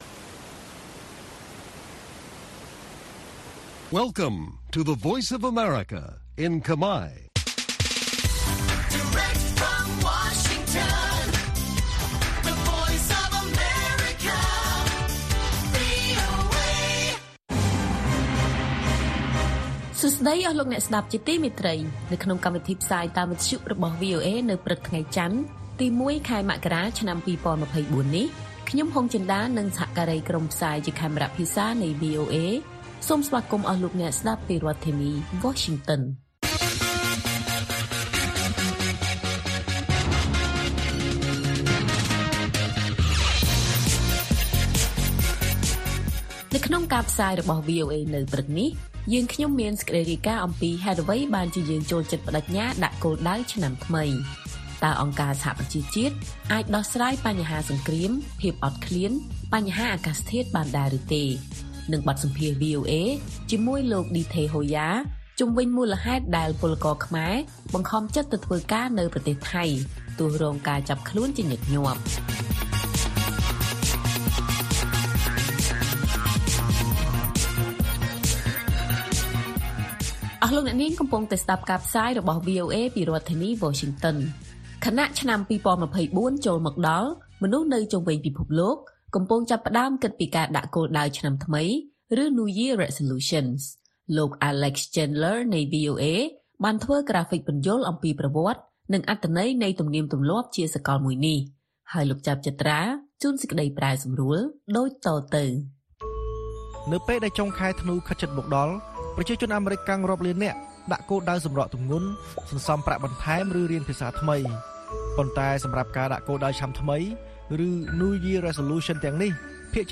ព័ត៌មានពេលព្រឹក ១ មករា៖ ហេតុអ្វីបានជាយើងចូលចិត្តប្តេជ្ញាដាក់គោលដៅឆ្នាំថ្មី?